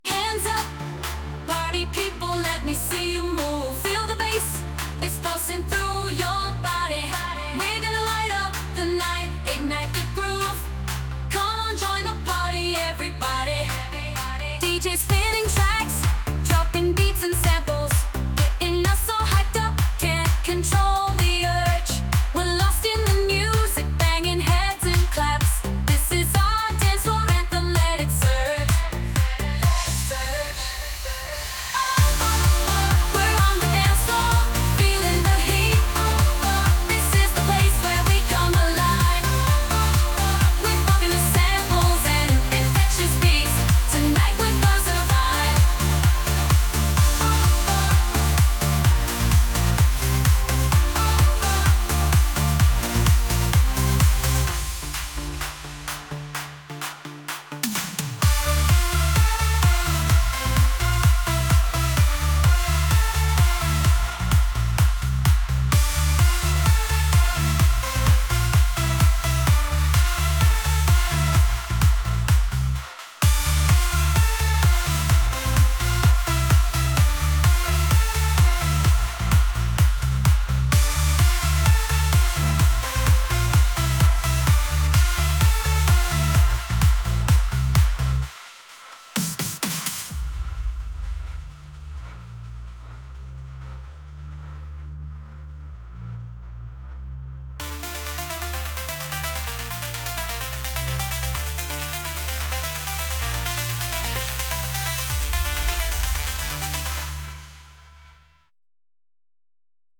AI Pop / Dance
Experience the best of AI-generated pop music.